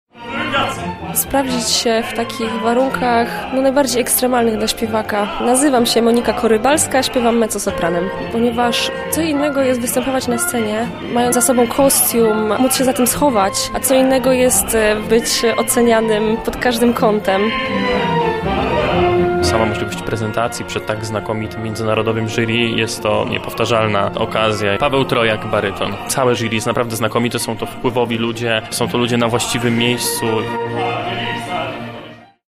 O tym co daje udział w konkursie mówią uczestnicy.